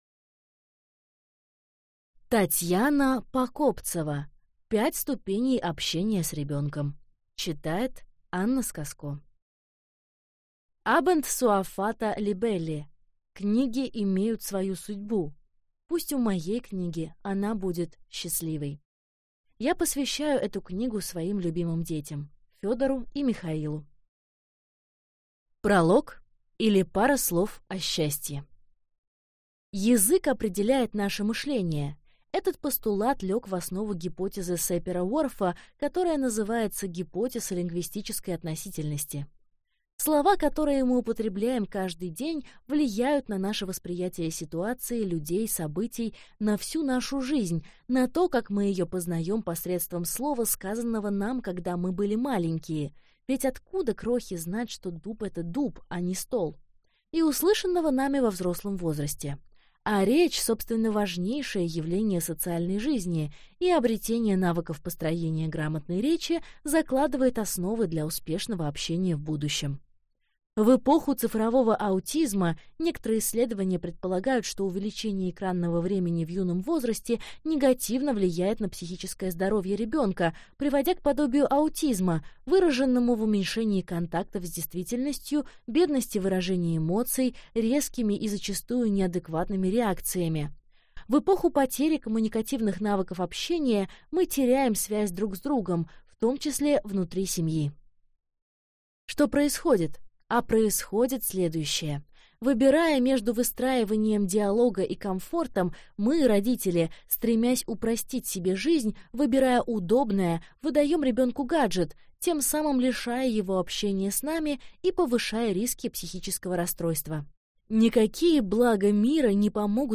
Аудиокнига 5 ступеней общения с ребенком | Библиотека аудиокниг